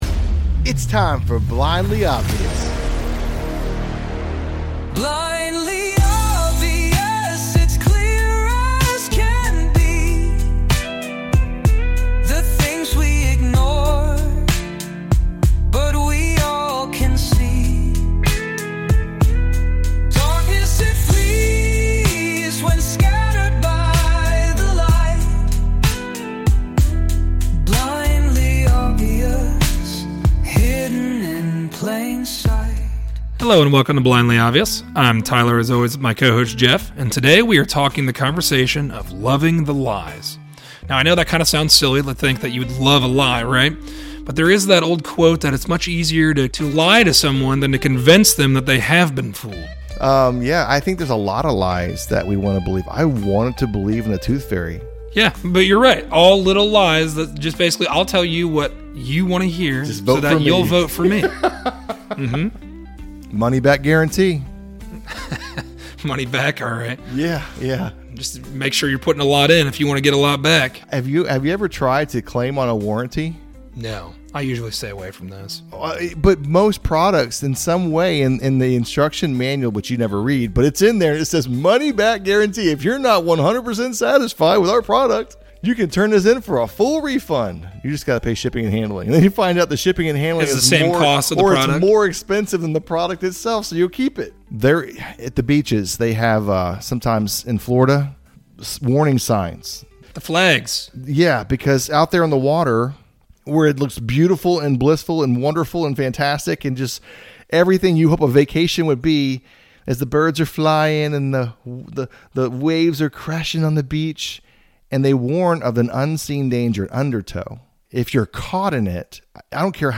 A conversation about the lies we love to tell ourselves. Whether it is from eating an extra snack, drinking an extra drink, we make excuses to justify our choices